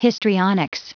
Prononciation du mot histrionics en anglais (fichier audio)
Prononciation du mot : histrionics